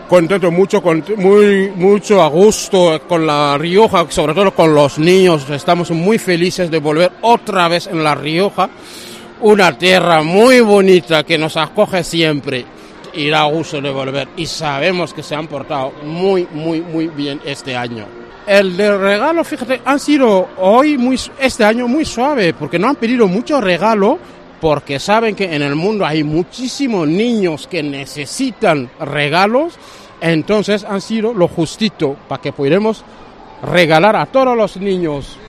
Rey Baltasar